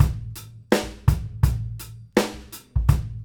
GROOVE 150EL.wav